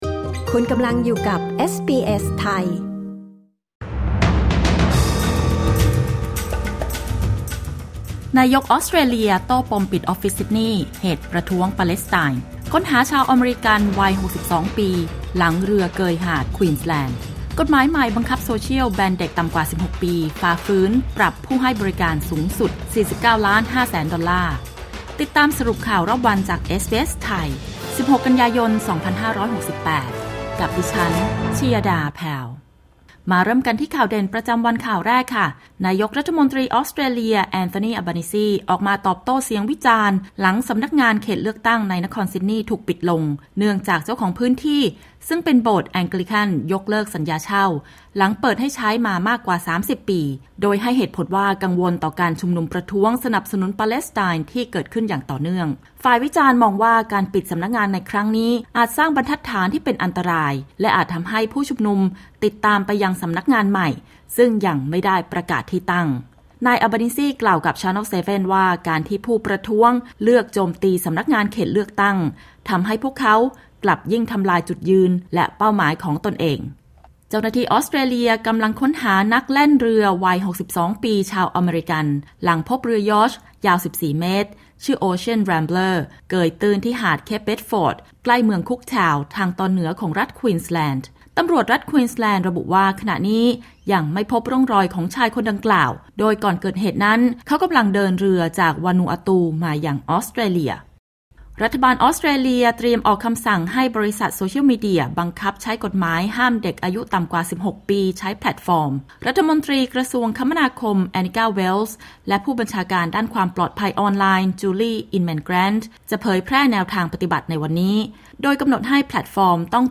สรุปข่าวรอบวัน 16 กันยายน 2568